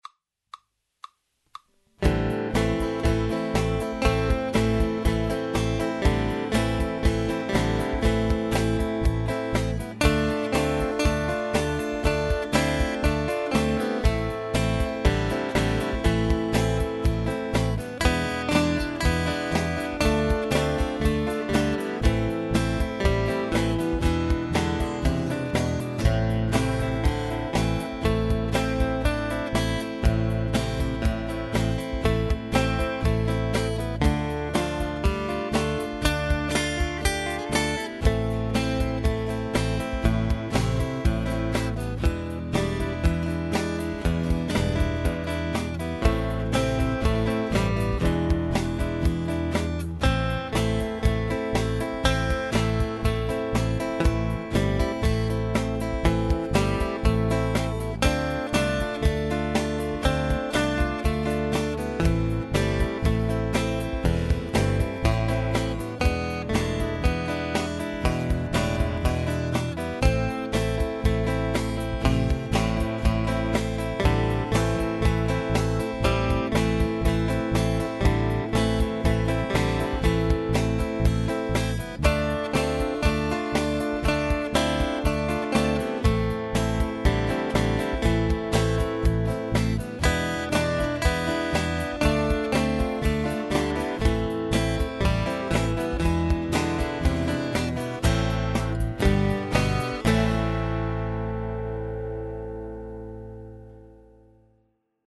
guitar ensembles in a variety of styles